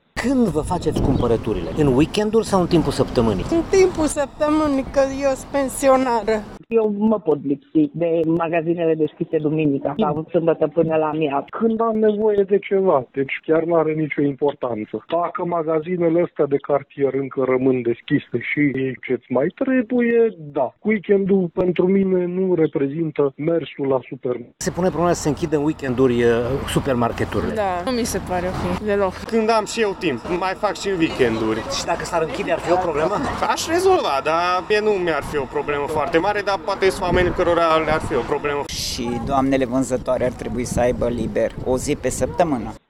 Părerile târgumureșenilor sunt împărțite, unii preferă să meargă la cumpărături în weekend, iar alții în timpul săptămânii, dar celor mai mulți le este indiferent, spun că s-ar adapta la orice program: